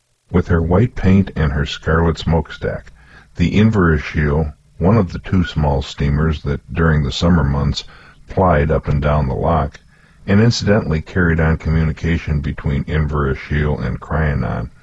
Reconstructed Audio from Spectrogram
You can hear that the reconstructed audio is not exactly the same as the original.
The Griffin-Lim algorithm provides a reasonable approximation, but there will always be some differences compared to the original audio.
reconstructed_libri1_voice.wav